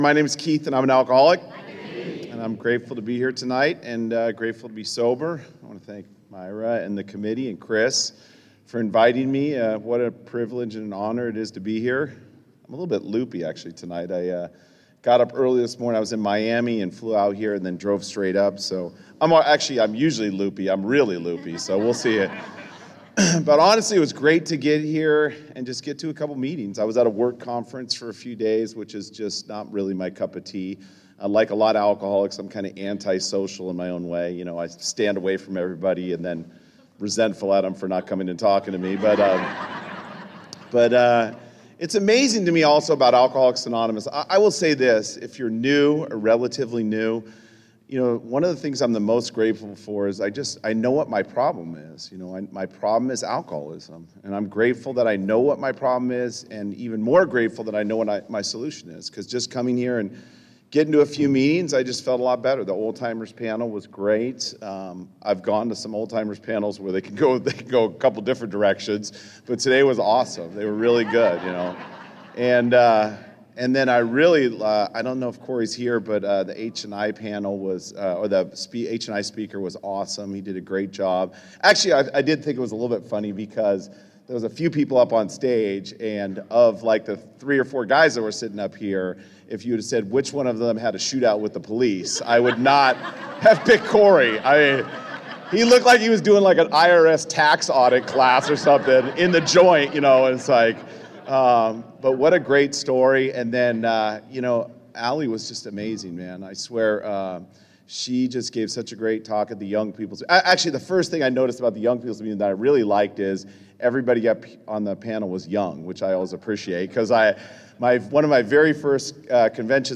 35th Annual Ventura Serenity By The Sea